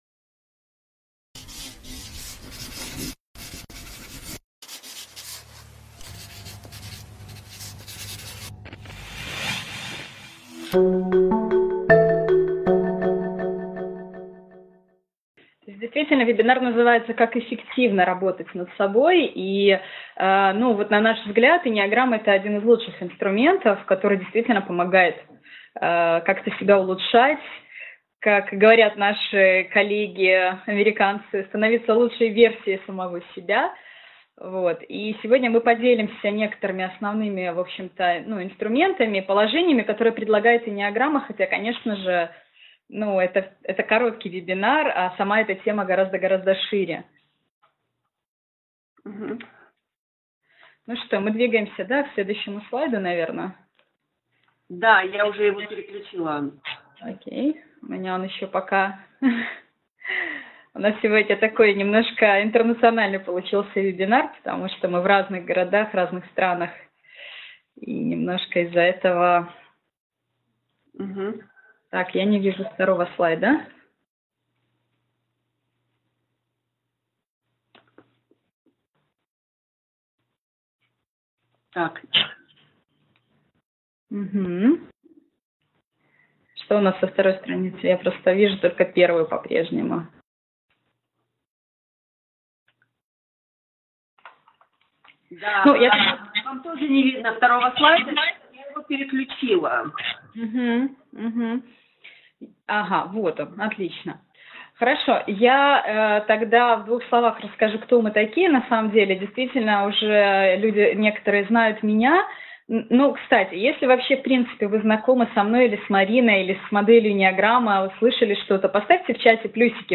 Аудиокнига Как эффективно работать над собой | Библиотека аудиокниг